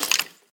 脚步声
骷髅行走时随机播放这些音效
Minecraft_Skeleton_skeleton_step3.mp3